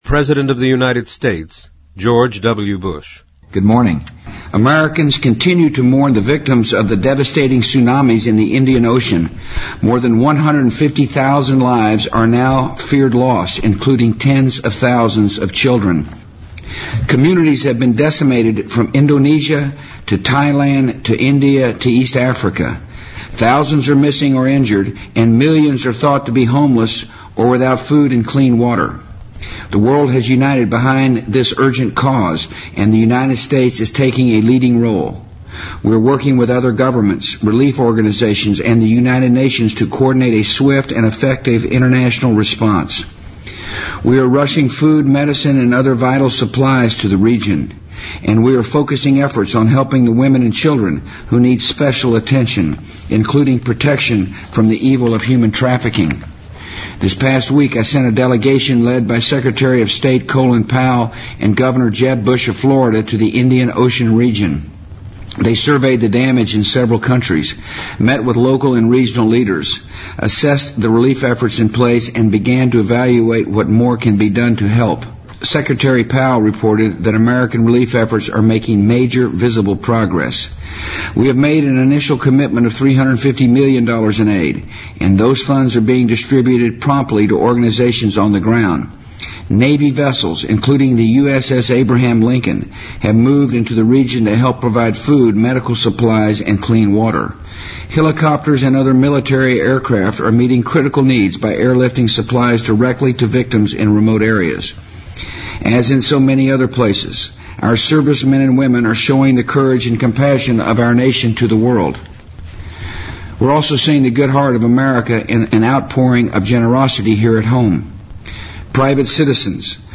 President Bush-2005-01-08电台演说 听力文件下载—在线英语听力室